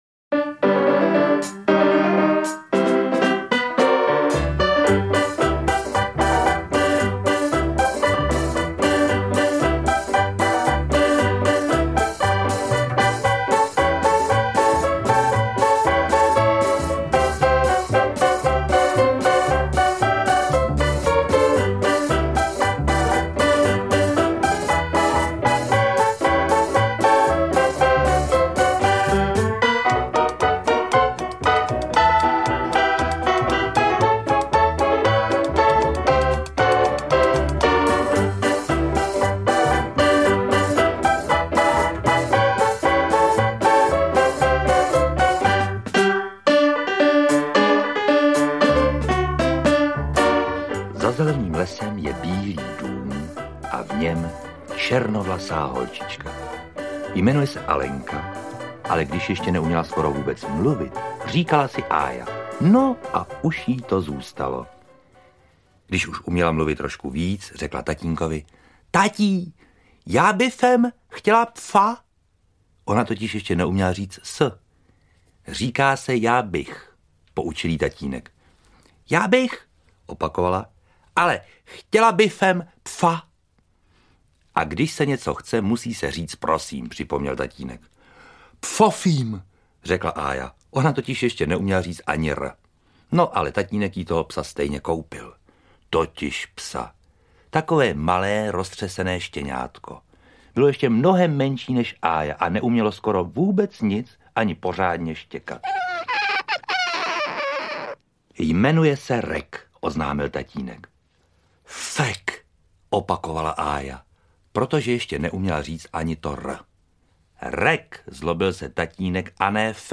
To nejlepší pro malé i velké posluchače - audiokniha obsahuje výběr toho nejlepšího z nahrávek pro malé posluchače, který zaujme celou rodinu.